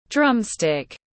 Cái dùi trống tiếng anh gọi là drumstick, phiên âm tiếng anh đọc là /ˈdrʌm.stɪk/
Drumstick /ˈdrʌm.stɪk/